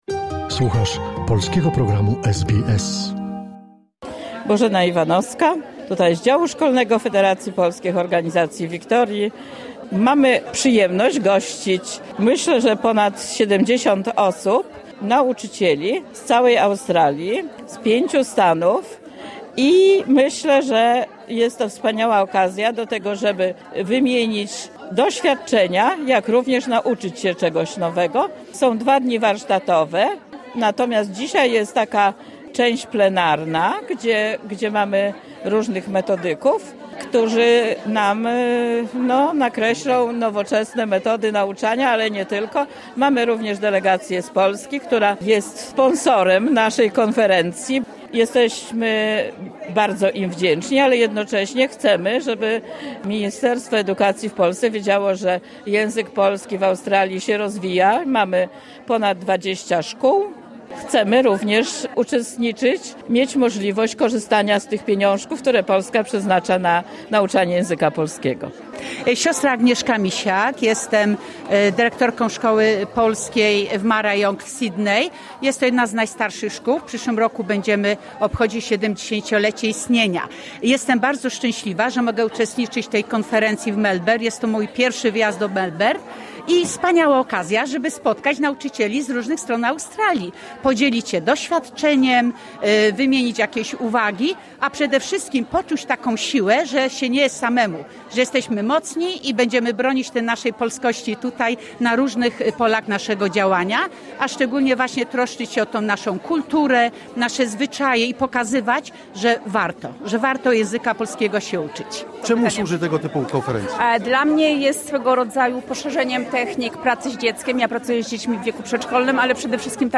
Relacja z Ogólnokrajowej Konferencji dla Nauczycieli Języka Polskiego, która odbyła się w dniach 26-28 września 2025 w Melbourne. Konferencję zorganizowała Komisja Oświatowa Polonii Australijskiej przy wsparciu Federacji Polskich Organizacji w Wiktorii i Polskiego Biura Opieki Społecznej „PolCare”.